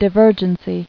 [di·ver·gen·cy]